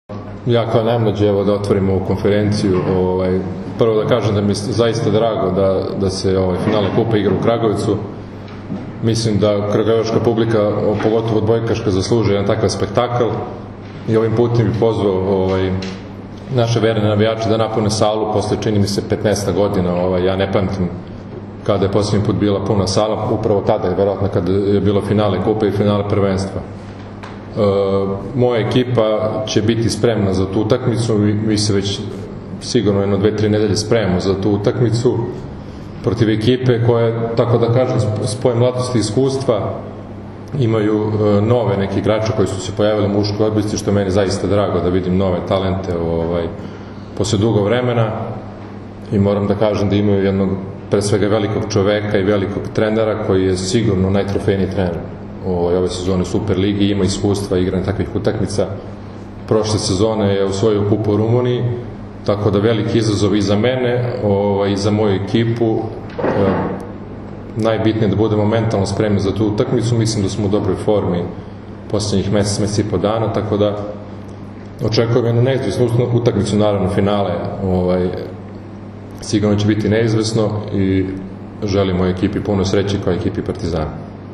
U beogradskom hotelu “M” danas je održana konferencija za novinare povodom finalnih utakmica 59. Kupa Srbije 2024/2025. u konkurenciji odbojkašica i jubilarnog, 60. Kupa Srbije 2024/2025. u konkurenciji odbojkaša, koje će se odigrati se u “MTS hali Jezero” u Kragujevcu u nedelju, 23. februara.
Izjava